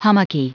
Prononciation du mot hummocky en anglais (fichier audio)
Prononciation du mot : hummocky